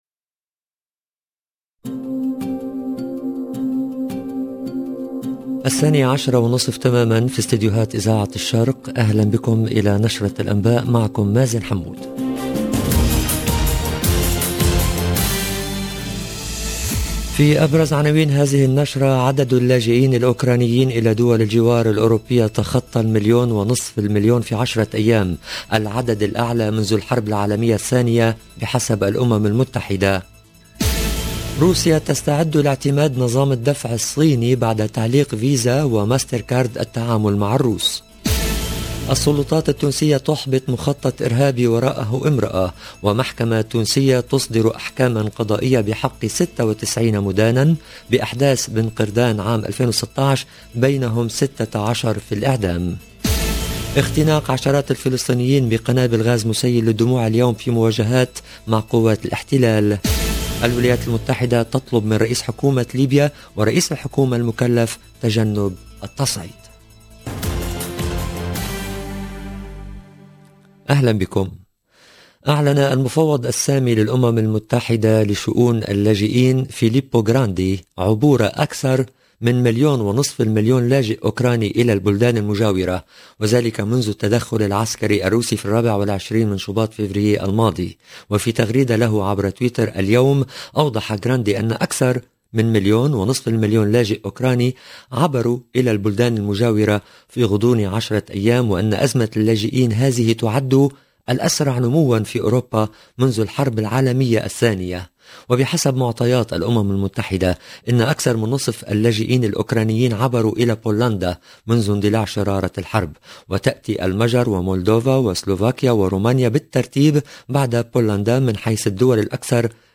LE JOURNAL DE 12H30 EN LANGUE ARABE DU 6/3/2022